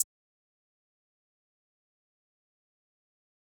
Hi Hat (OZ).wav